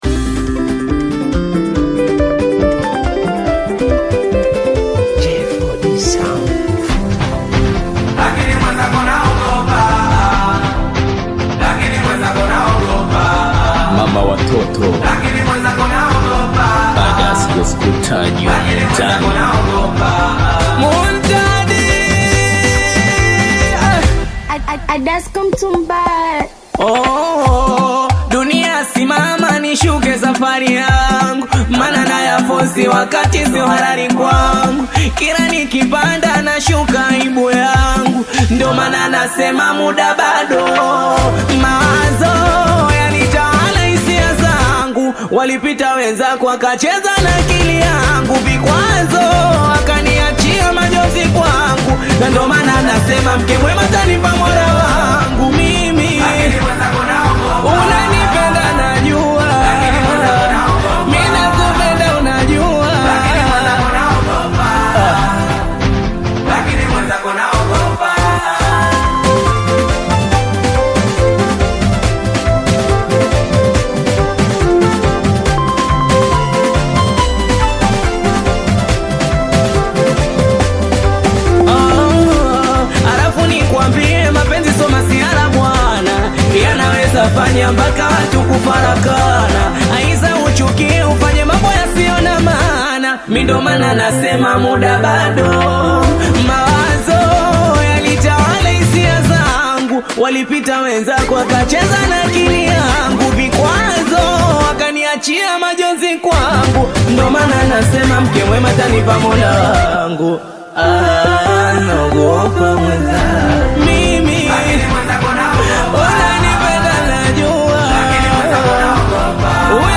Singeli music track
Tanzanian Bongo Flava singeli